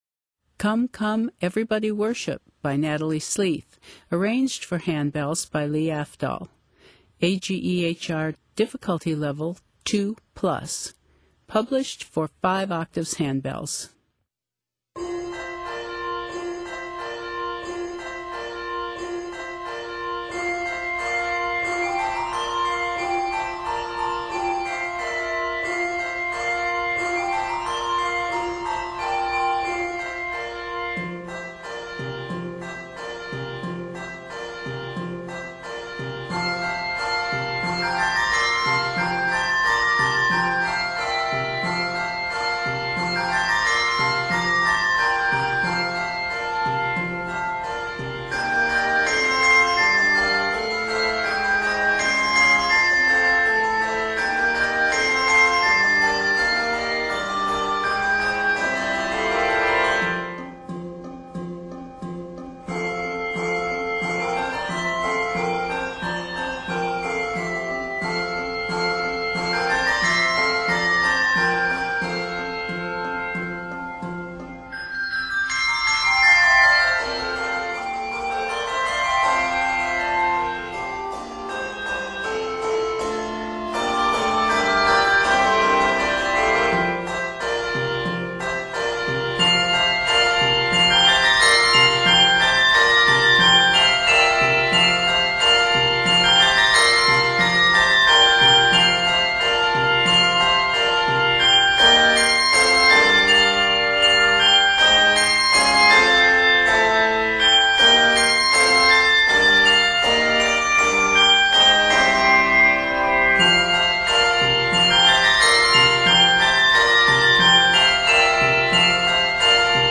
handbell setting